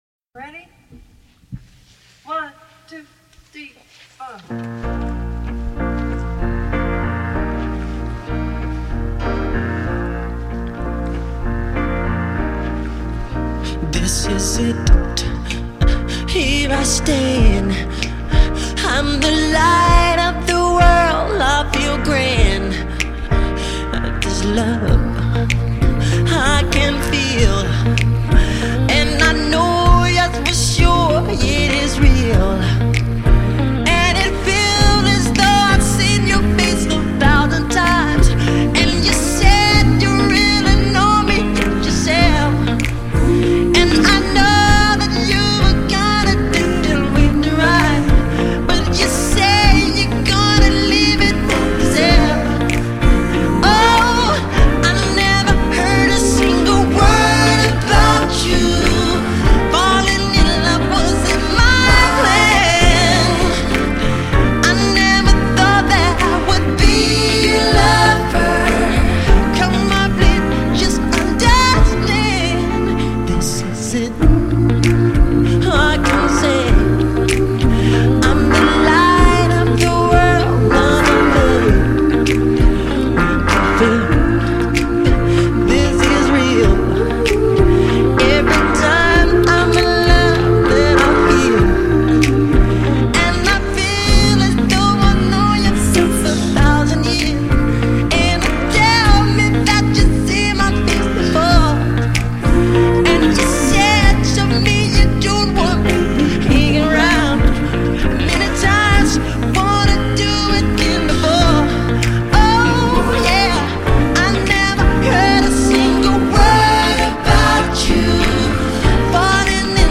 專輯流派：Pop